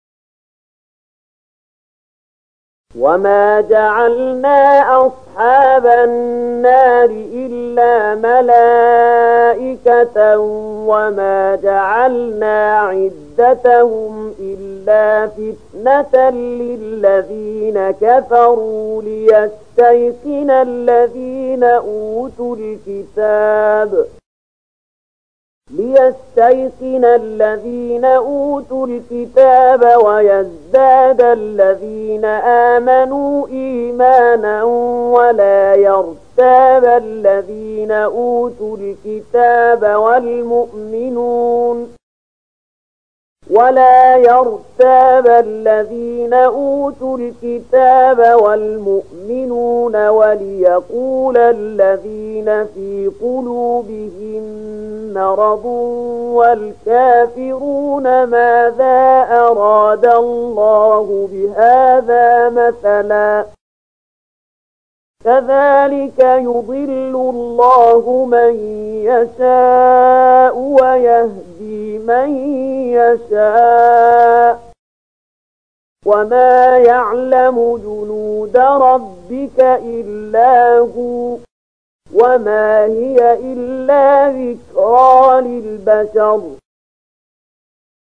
074031 Surat Al-Muddatstsir ayat 31 bacaan murattal ayat oleh Syaikh Mahmud Khalilil Hushariy: